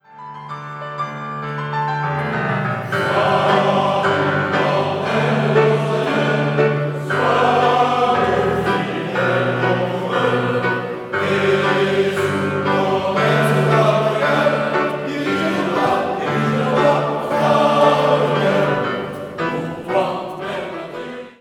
Un chœur d’hommes
Selon les œuvres, le choeur chante a cappella ou avec accompagnement instrumental et se produit parfois en partenariat avec d’autres ensembles.